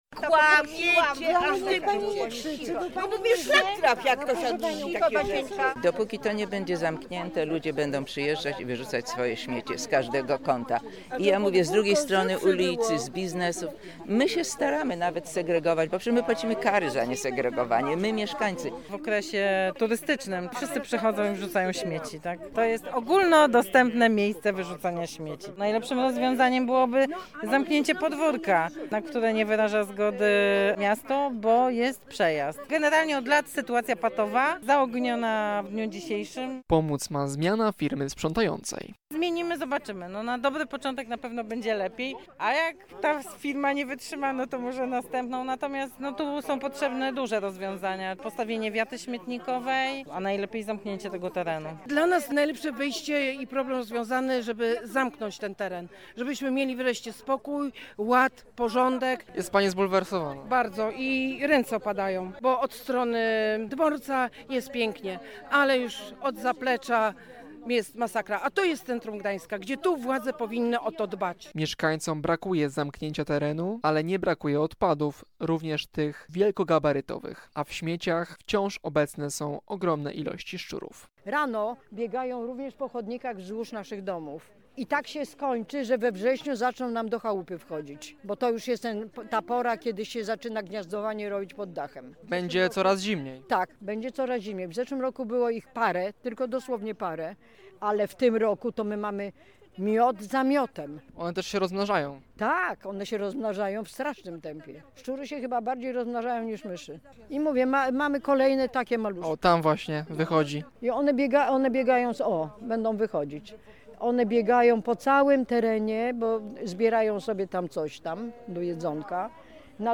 Wypowiedzi mieszkańców można posłuchać w materiale naszego reportera: